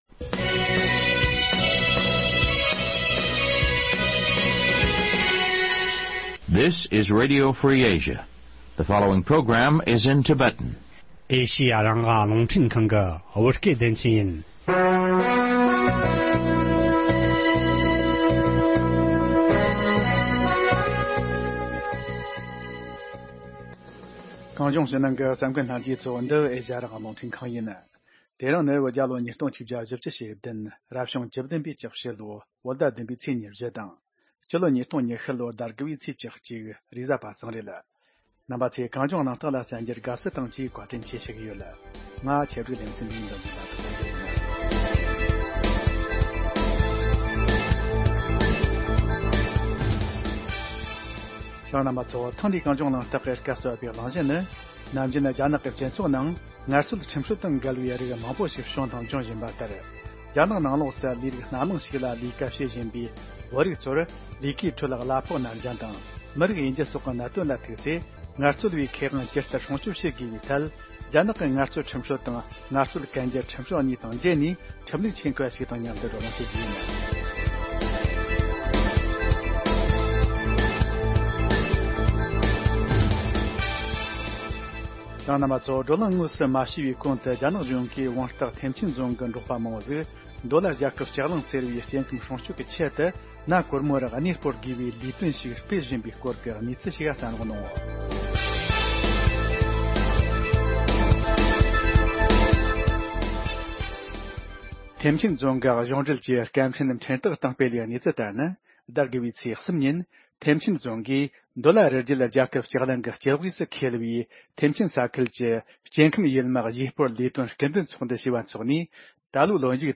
བགྲོ་གླེང་ཞུས་པ་གསན་རོགས་གནང་།